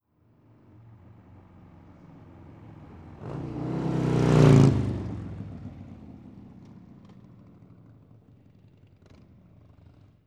Motorcycle Fast By 01.wav